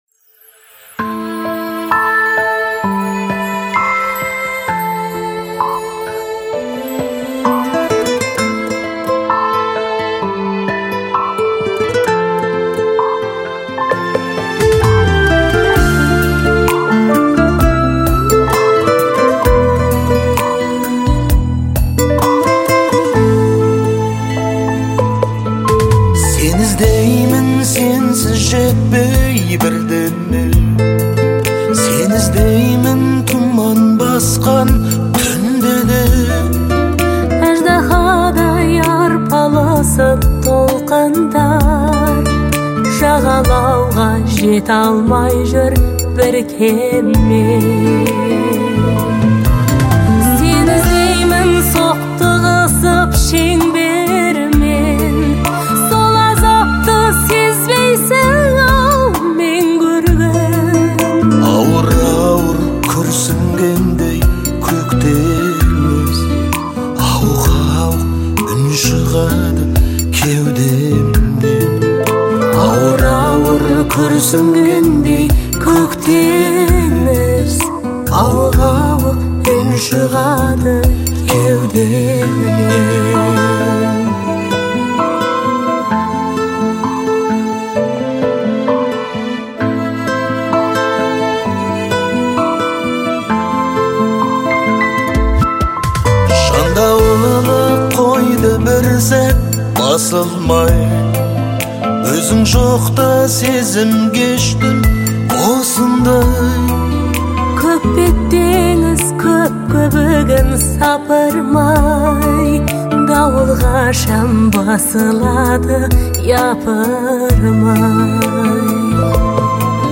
• Категория: Казахские песни